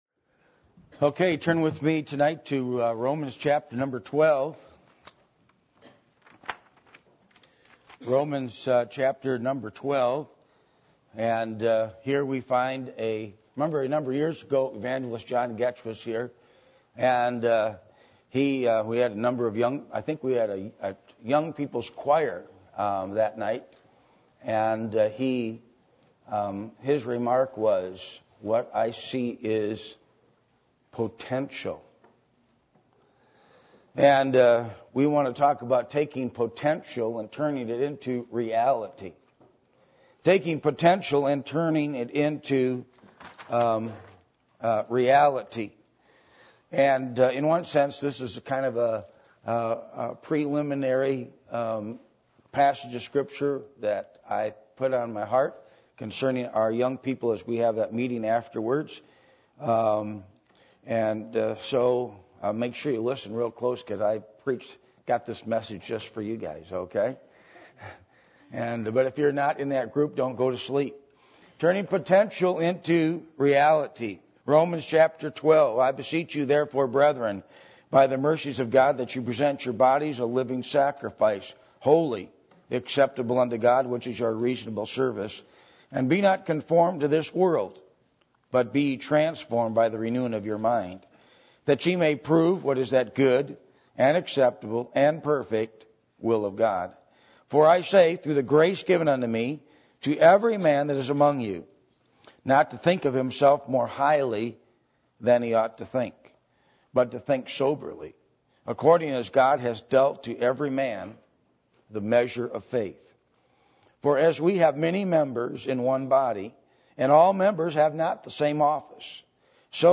Romans 12:1-11 Service Type: Sunday Evening %todo_render% « The Last Days Of The Church Age